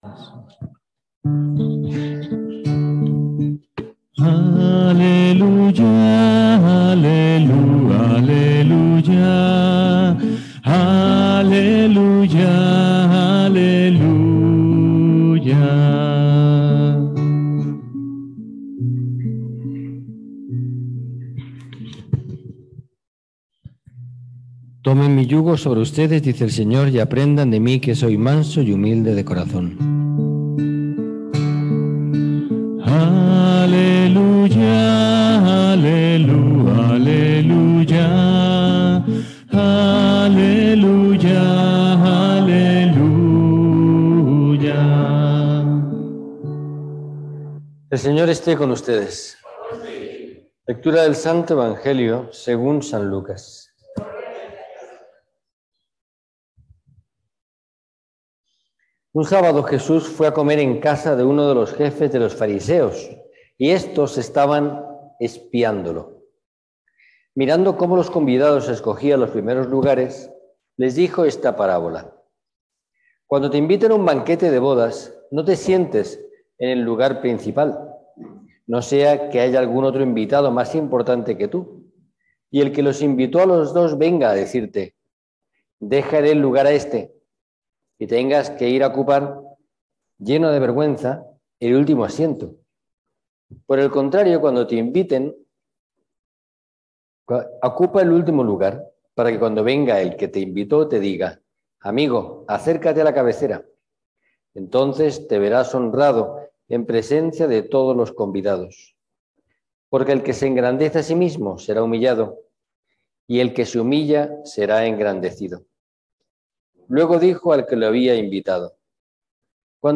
Si le dejaba hacer Dios podría conseguir que todos los caminos me llevaran al lugar esperado, deseado » Compartir Facebook Twitter Correo electrónico Imprimir Publicado en Homilias